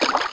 step_water.wav